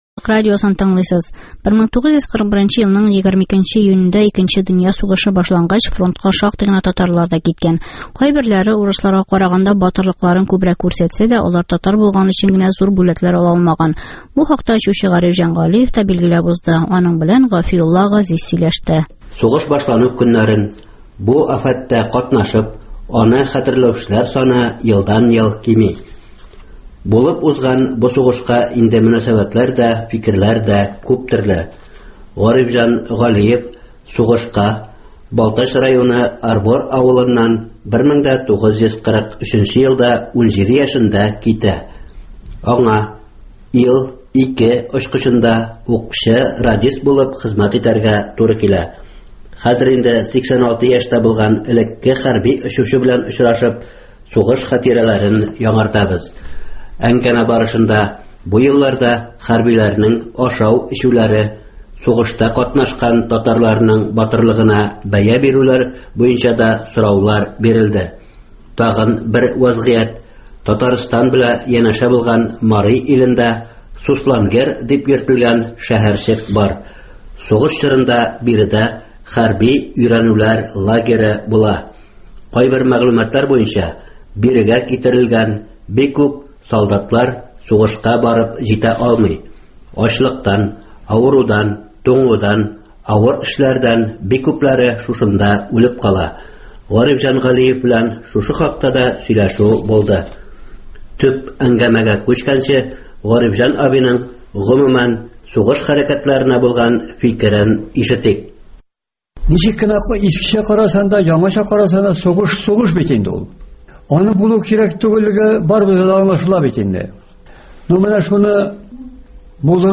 әңгәмә